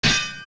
OOT_Sword_Clang3.wav